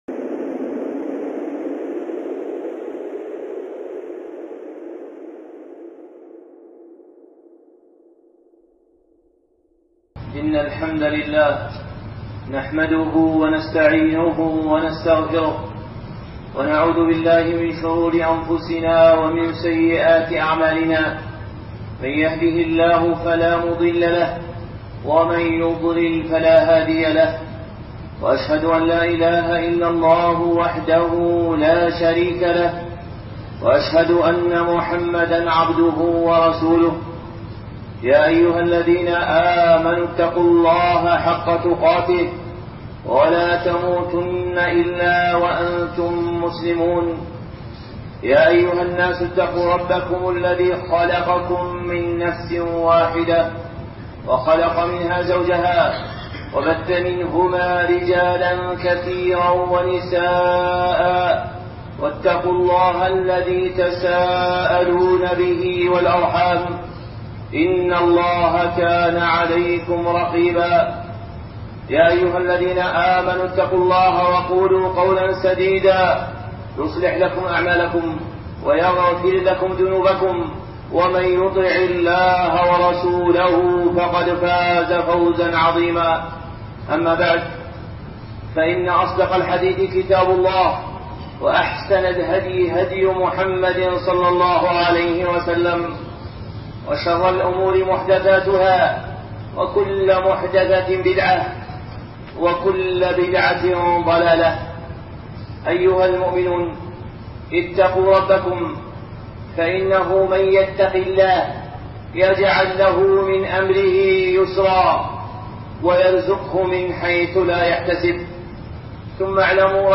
خطبة
الخطب المنبرية